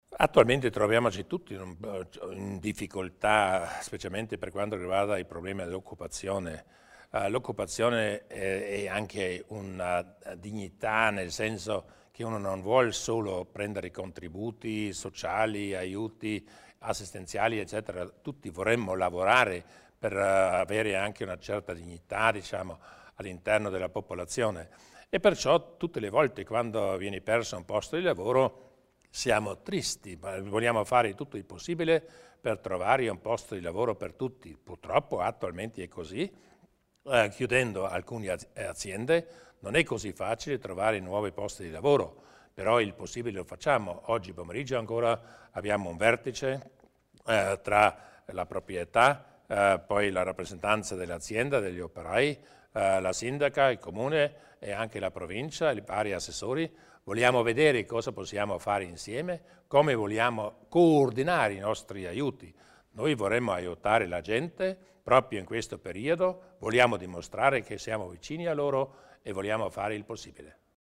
Il Presidente Durnwalder spiega gli interventi a sostegno delle imprese in difficoltà